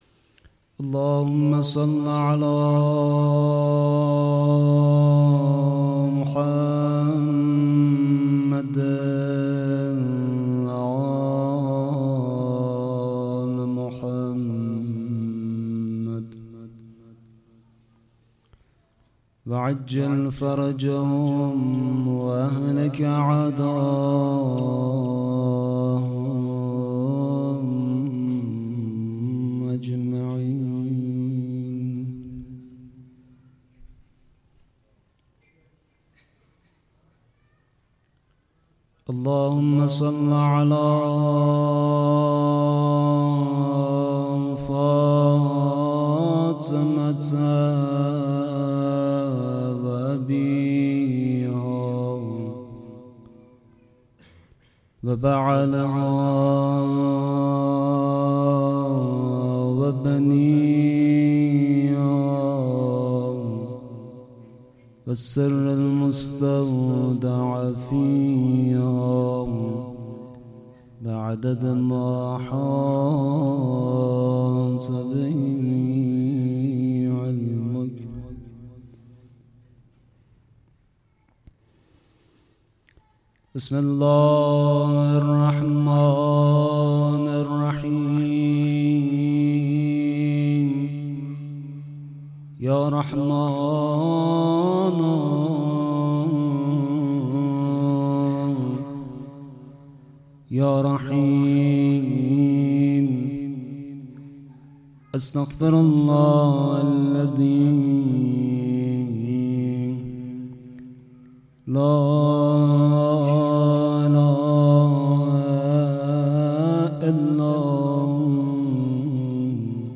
شام غریبان محرم 98 - زیارت عاشورا